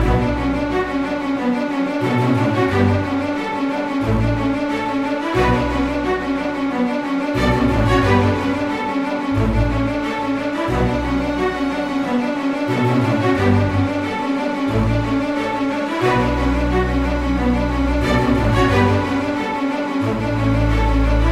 描述：我认为这些循环是完美的hiphop或电影风格，你可以用这四个循环和你的创造力制作一个节拍。
标签： 90 bpm Hip Hop Loops Strings Loops 1.79 MB wav Key : Unknown
声道立体声